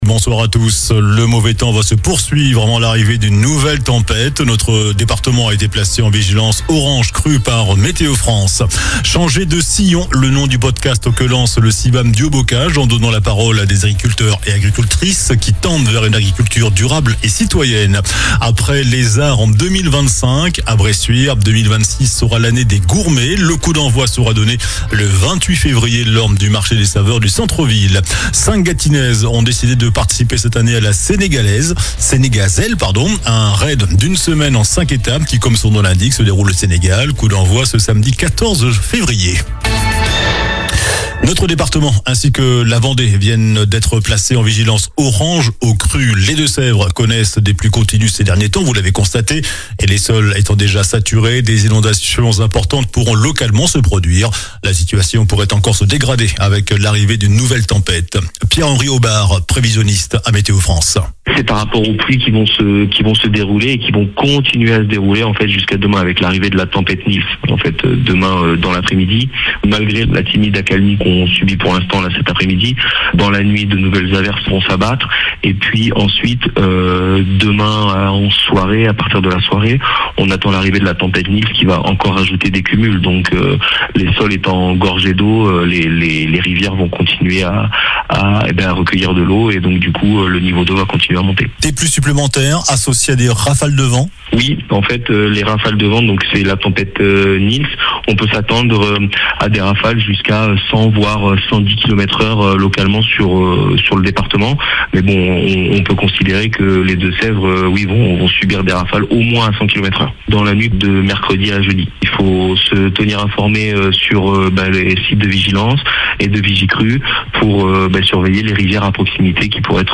JOURANL DU MARDI 10 FEVRIER ( SOIR )